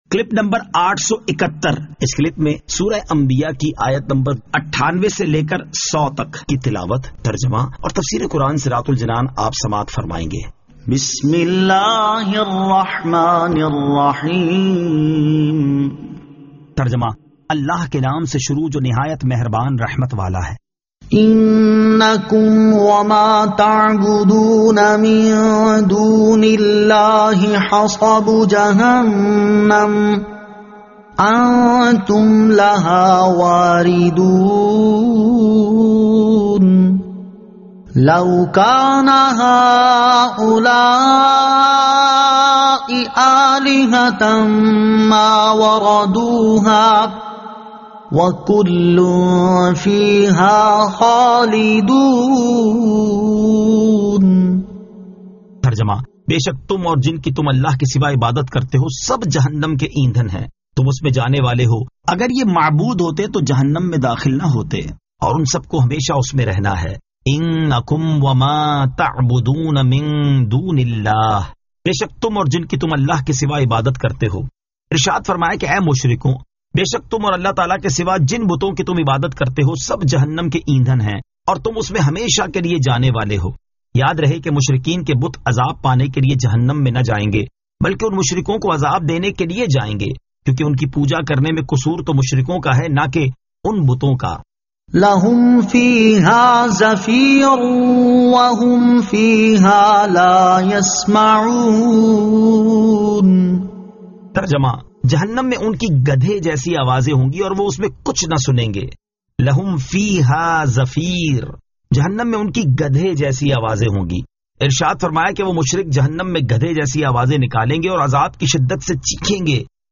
Surah Al-Anbiya 98 To 100 Tilawat , Tarjama , Tafseer